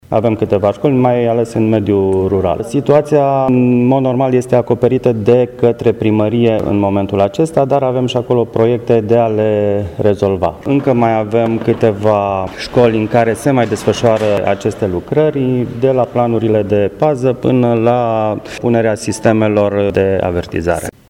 La acest din urmă capitol, există și o neîmplinire majoră, aceea a școlilor fără pază, care, ar putea fi rezolvată în viitorul apropiat, după cum a precizat inspectorul școlar general, Ion Negrilă: